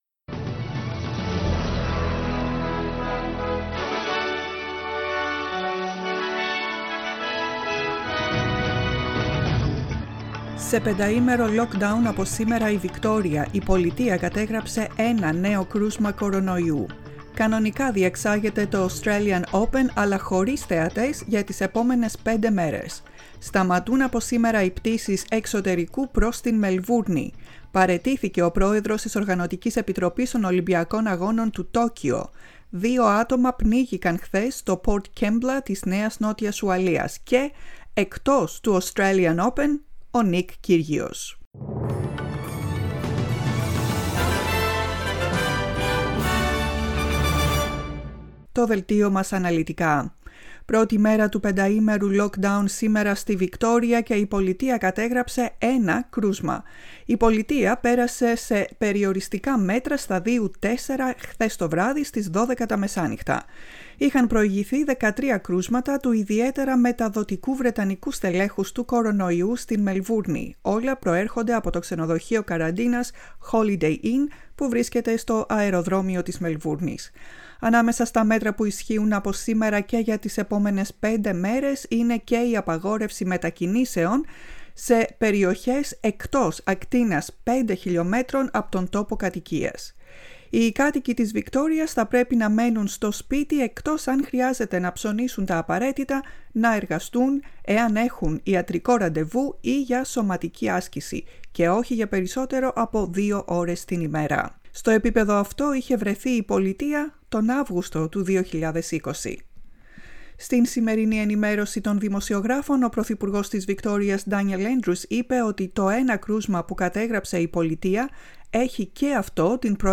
Δελτίο Ειδήσεων στα Ελληνικά, 13.2.2021